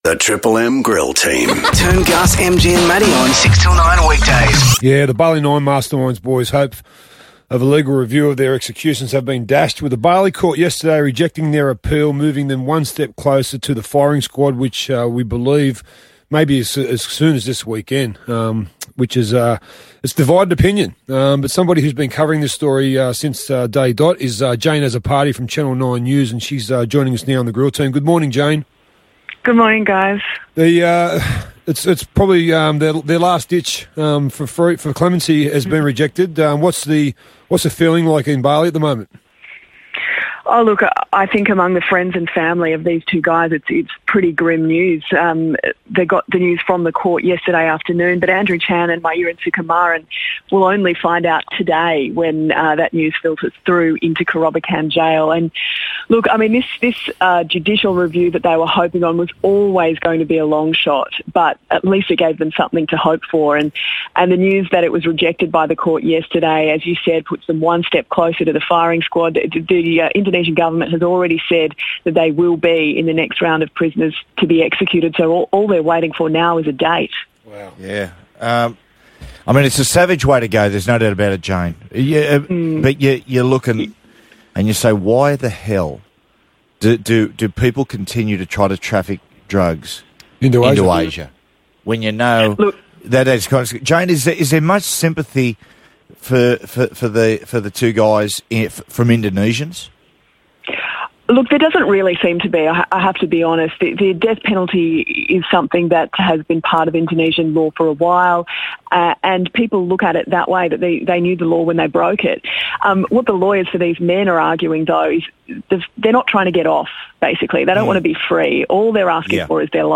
spoke to the Triple M Grill Team: An Indonesian court has denied Australian drug smugglers Andrew Chan and Myuran Sukumaran the chance to have another judicial review of their death sentences, meaning there is now nothing stopping their executions.